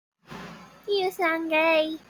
Weeeeee Efeito Sonoro: Soundboard Botão